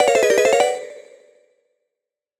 ゲーム効果音第28弾！切り替えやエフェクトにぴったりで、ちょっとレトロ系のキラキラ音です！